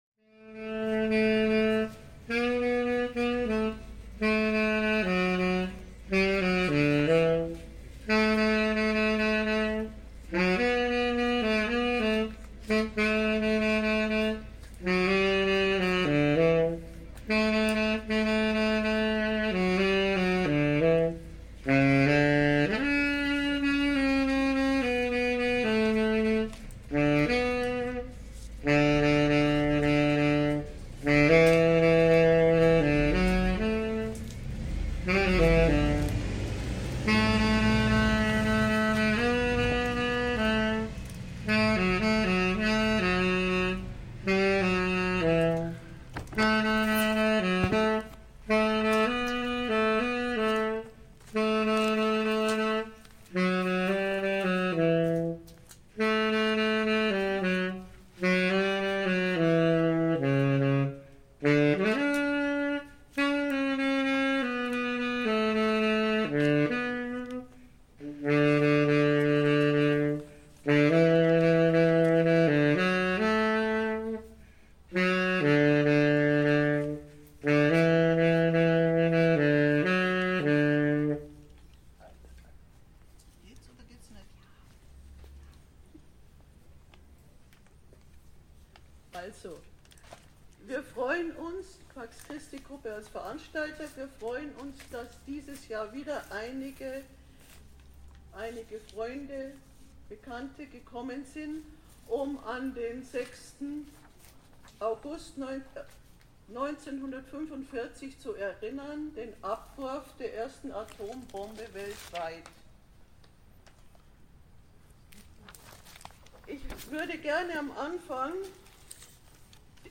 Texte von Persönlichkeiten und Zeitzeugen wurden ebenso vorgetragen wie eine Rückschau auf das gerade zu Ende gegangene erfolglose Vorbereitungstreffen der Staaten des Atomwaffensperrvertrages.
Das Gedenken endete mit einem gemeinsamen „We shall overcome“.
Gedenken am Friedenspfahl (mp3)